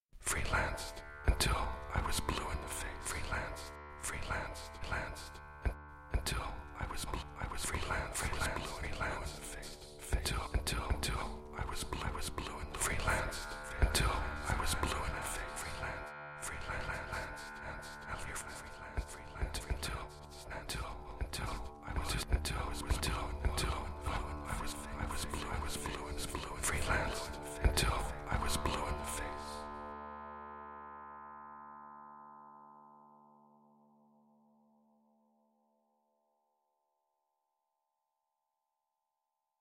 Mixing the processed and the clean vocal signal yields Paul Auster's voice to melt into the orchestral soundscape
Audio Example: Mixture of direct and processed sound